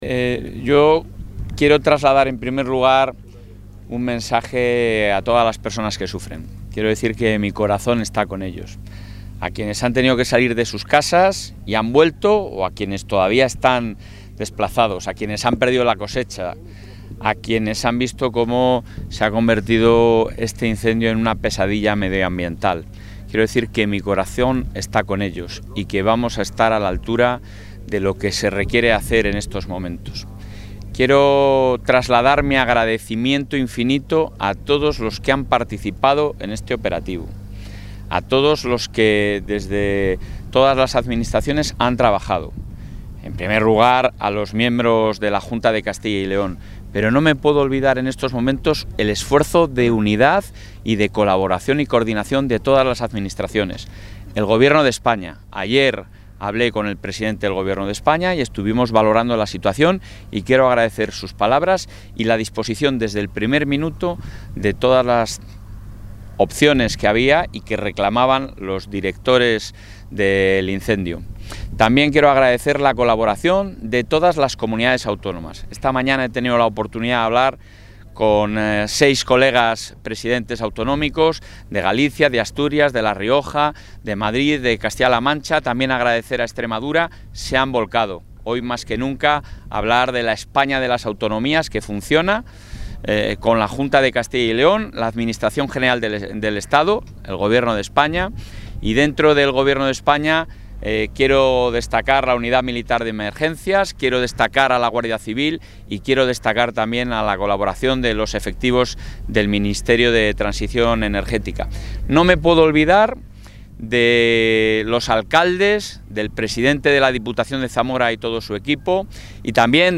El presidente de la Junta de Castilla y León, Alfonso Fernández Mañueco, ha acudido hoy al Puesto de Mando Avanzado del incendio de la Sierra de la Culebra.